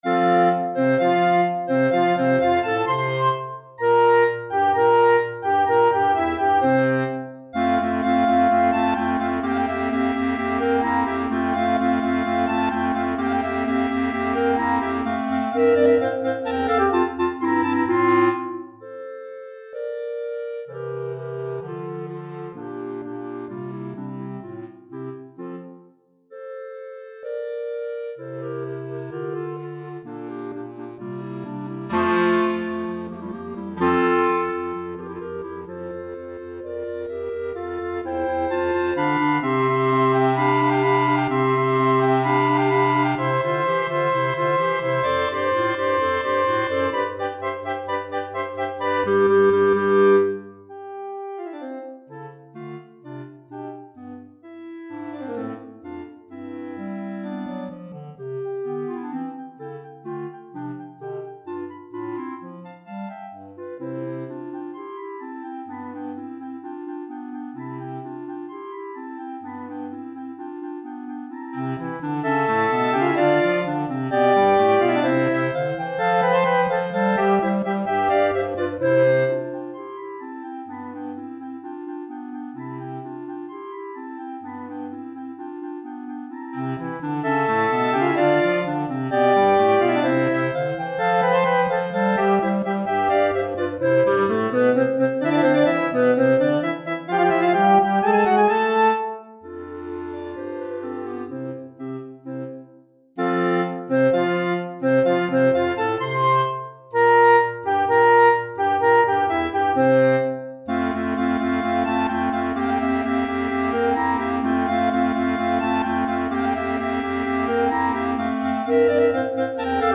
4 Clarinettes en Sib et Clarinette Basse